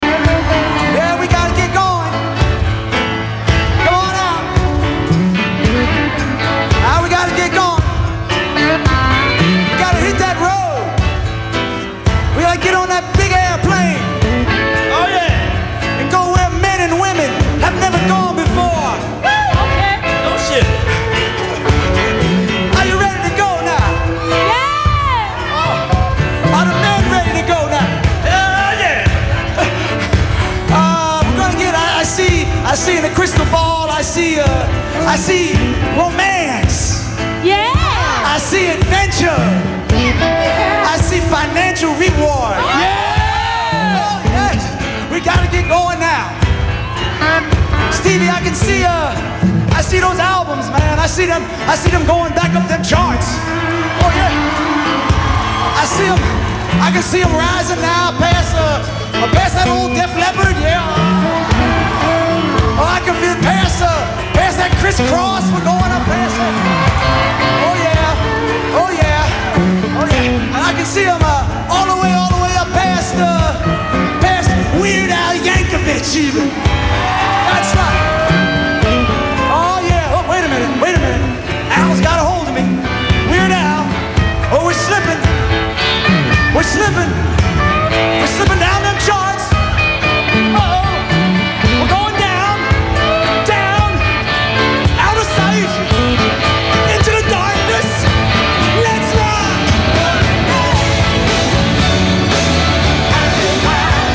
When Bruce’s Human Touch and Lucky Town albums failed to hold their spots on the album charts, Springsteen enviously but good-naturedly name-checked his friend from the stage in his 1992 rehearsal show and radio broadcast.